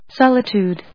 音節sol・i・tude 発音記号・読み方
/sάlət(j)ùːd(米国英語), sˈɔlətjùːd(英国英語)/